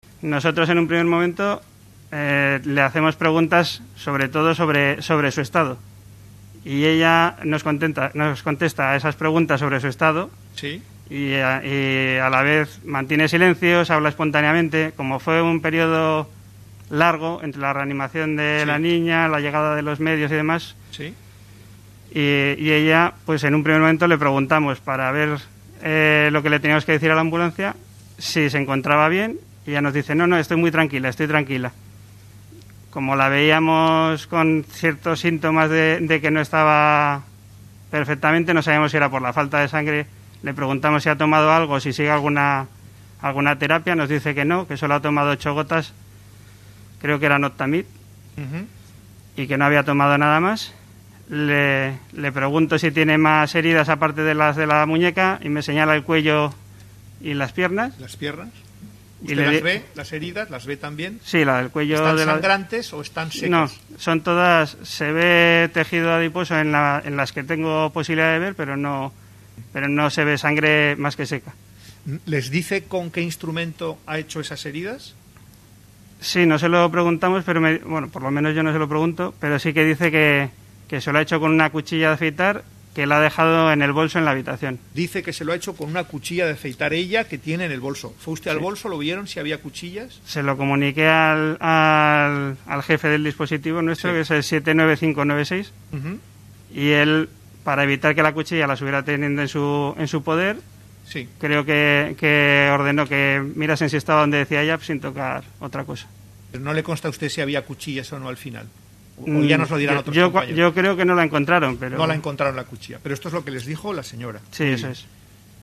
JUICIO-ELLA-SE-LESIONÓ_.mp3